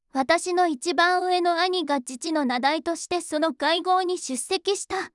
voicevox-voice-corpus
voicevox-voice-corpus / ita-corpus /もち子さん_怒り /EMOTION100_023.wav